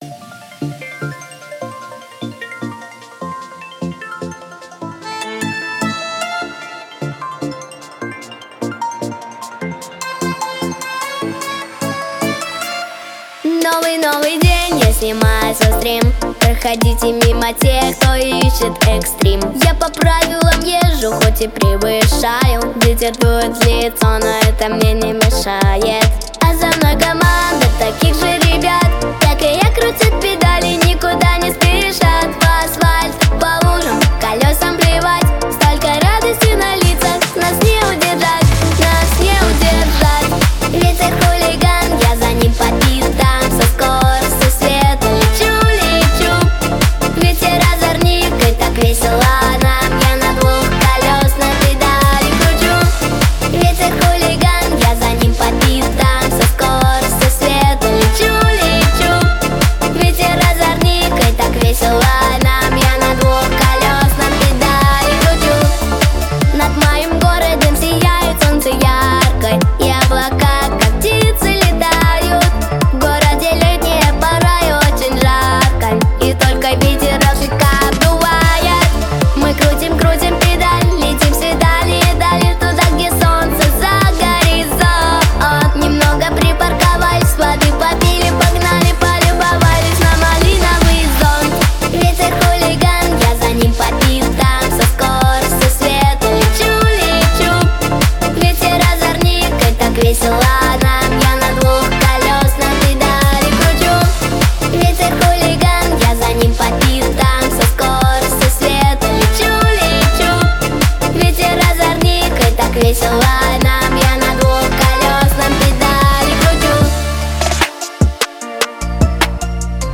• Категория: Детские песни
детская дискотека